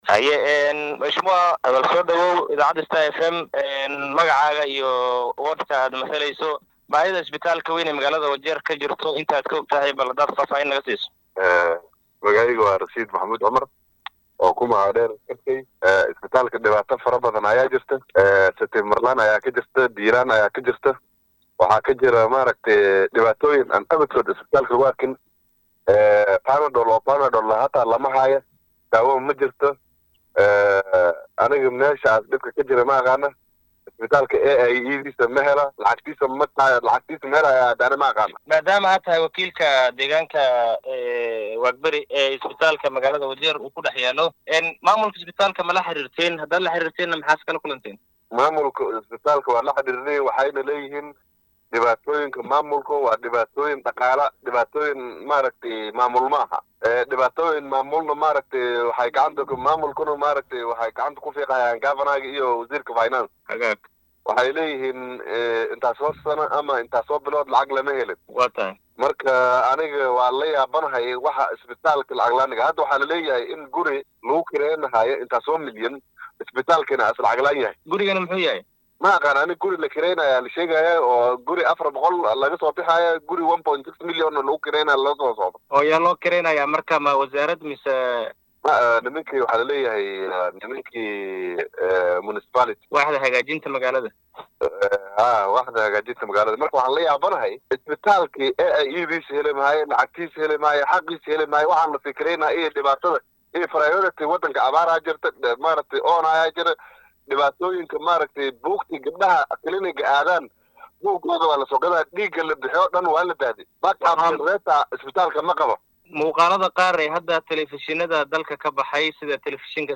Wakiilka laga soo doortay deegaanka hoose ee Wagberi ee ismaamulkaasi, Rashiid Maxamad oo wareysi gaar ah siiyay idaacadda Star Fm ayaa inooga warbixiyay dhibaatada ka jirta isbitaalka guud ee dowlad deegaanka Wajeer.